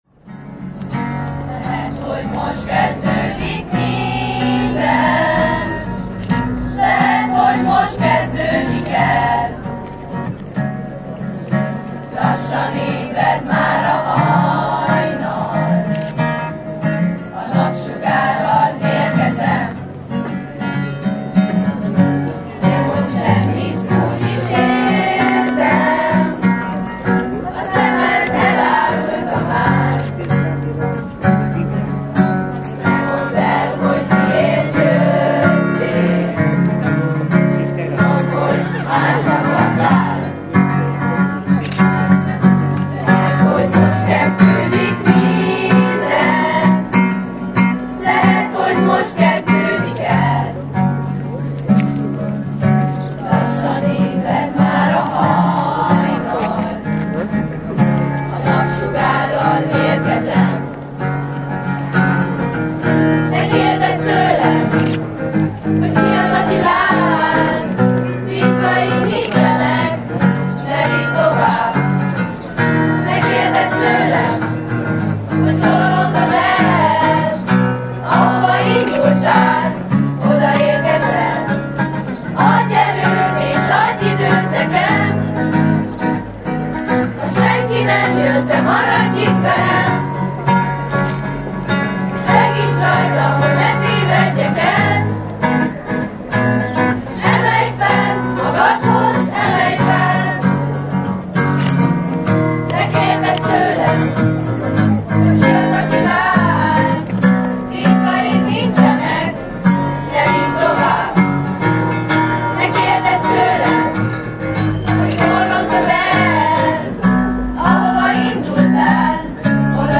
A végzős osztályok dallal búcsúztak.